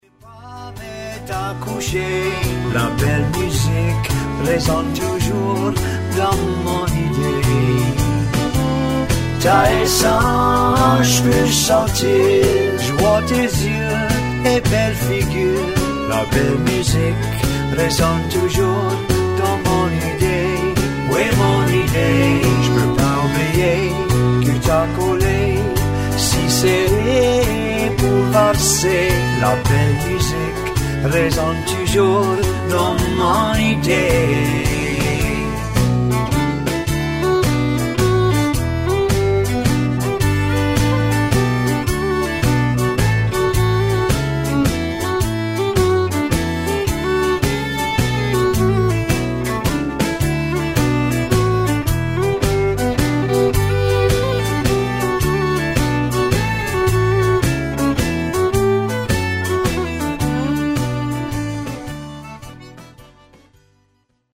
Accordion, Vocals and Harmony Vocals, Bass Guitar
Fiddle on 2
Drums on all